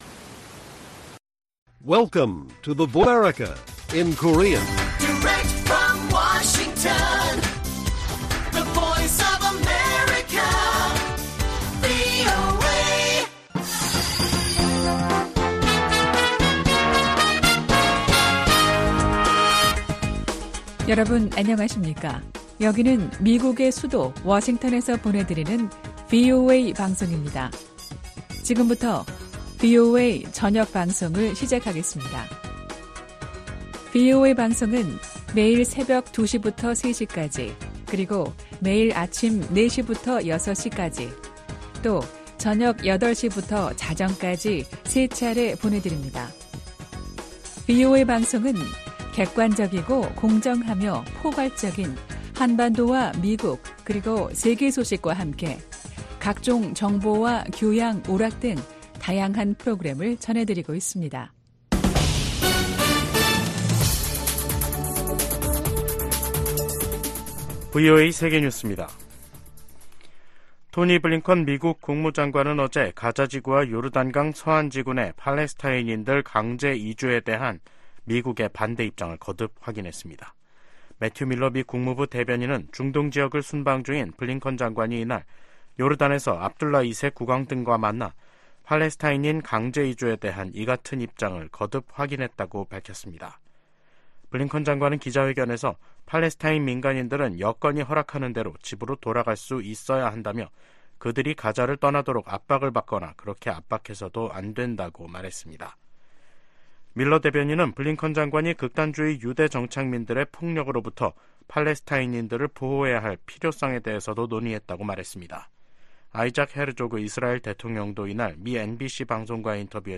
VOA 한국어 간판 뉴스 프로그램 '뉴스 투데이', 2024년 1월 8일 1부 방송입니다. 북한 군이 한국의 서북도서 인근에서 포 사격을 실시하자 한국 군도 해당 구역 군사훈련을 재개하기로 했습니다. 미 국무부는 북한의 서해 해상 사격에 도발 자제와 외교적 해결을 촉구했습니다.